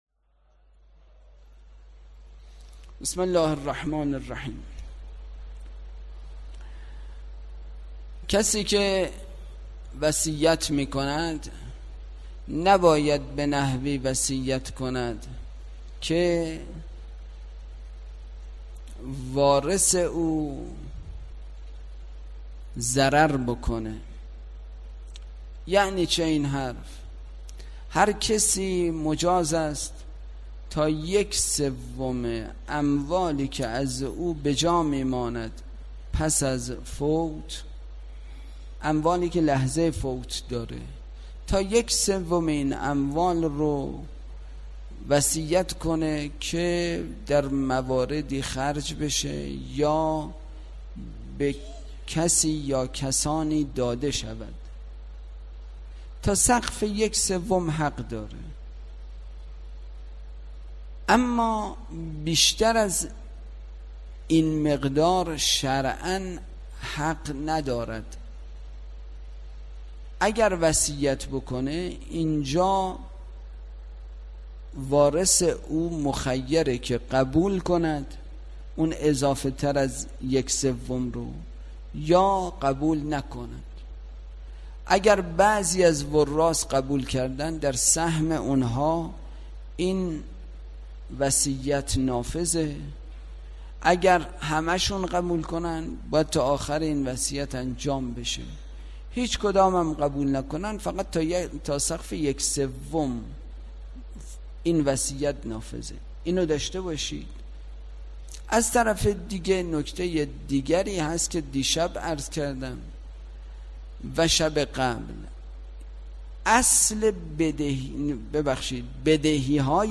برگزارکننده: مسجد اعظم قلهک